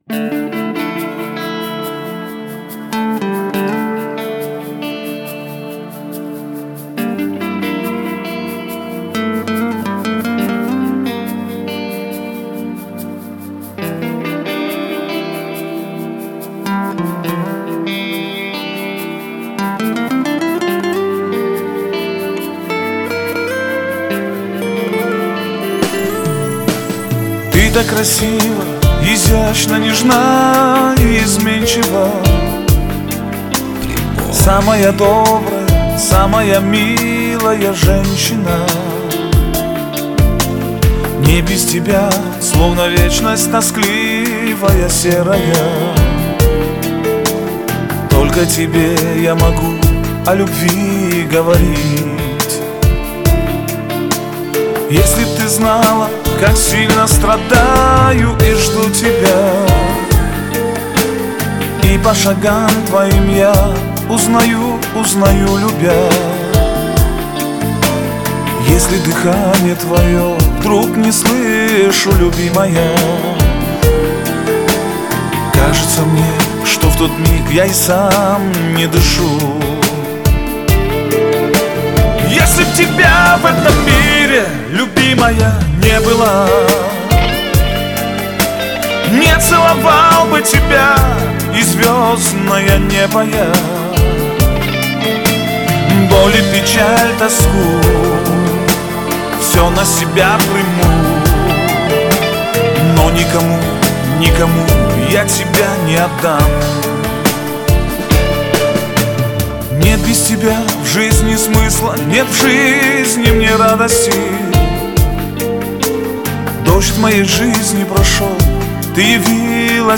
Категория: Восточная музыка » Кавказские песни